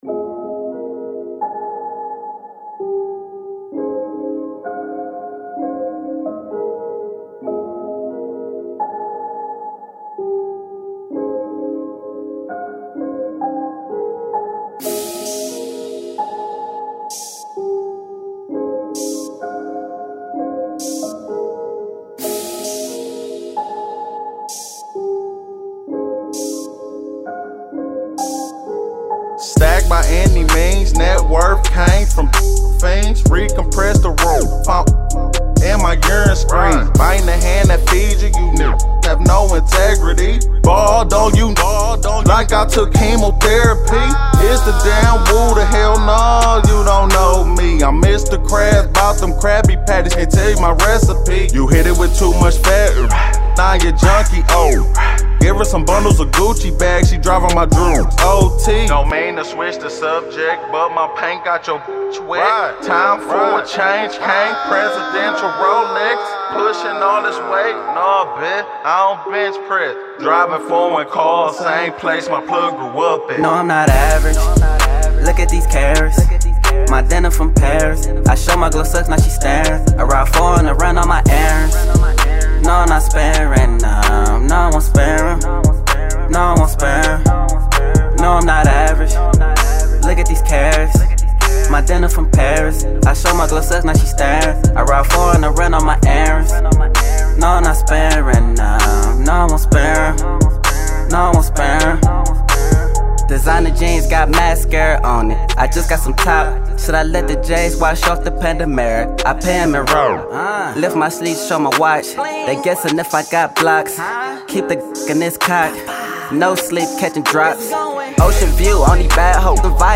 trap virtuoso